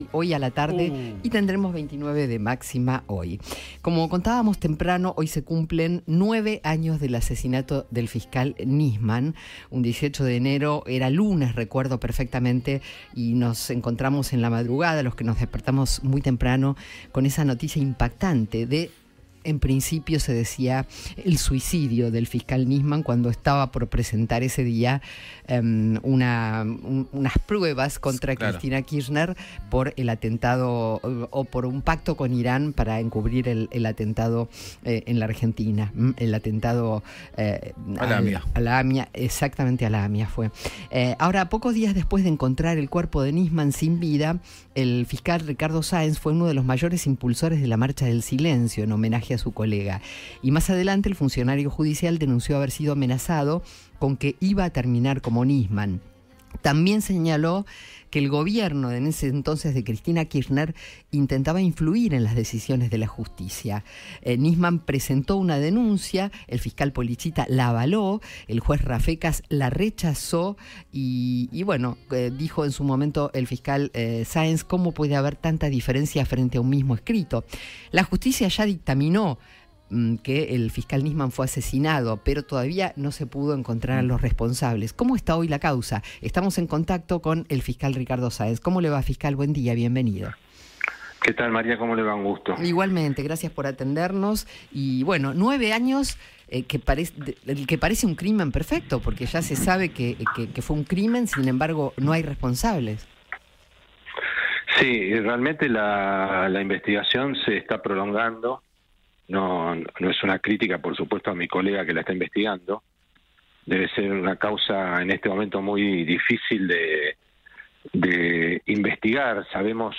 Ricardo Sáenz, fiscal de la Cámara Criminal y Correccional, conversó con el equipo de Alguien Tiene que Decirlo en un nuevo aniversario del asesinato del fiscal Alberto Nisman, ocurrido el 18 de enero de 2015.